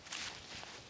dr_walk2.wav